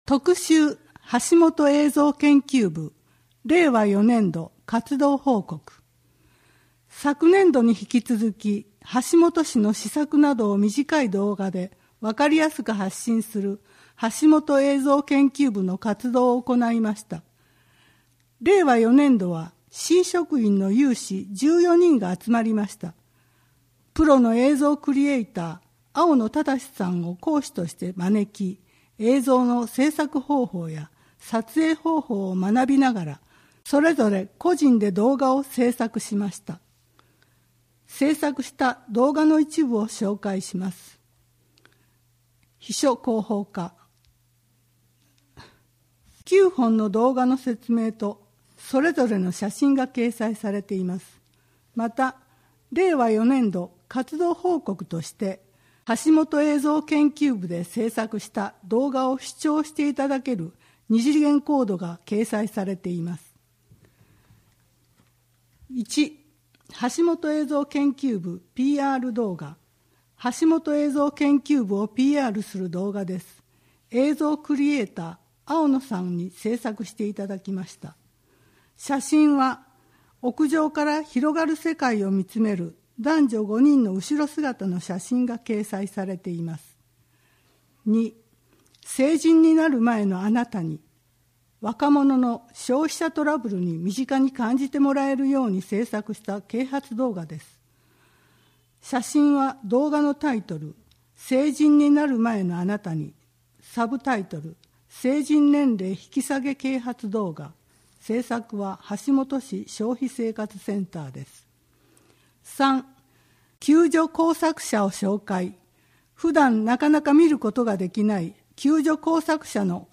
WEB版　声の広報 2023年5月号